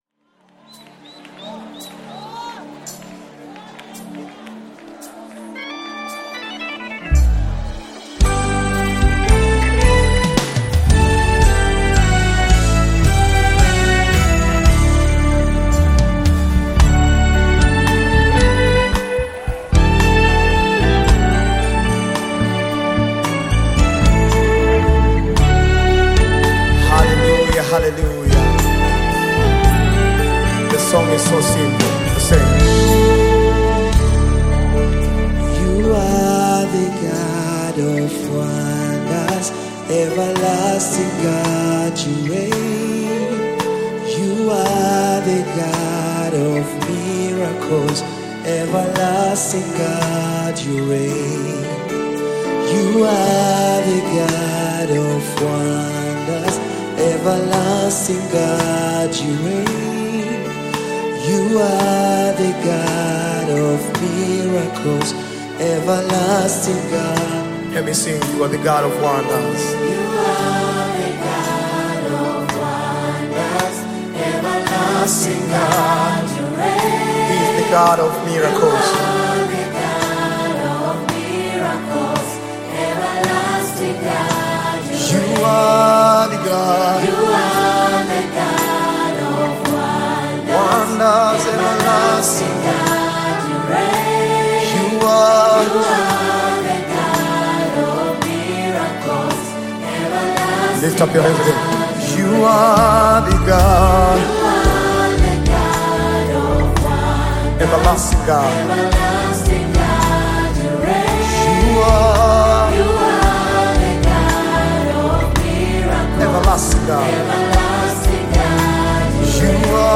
Nyimbo za Dini music
Gospel music track
Tanzanian Gospel group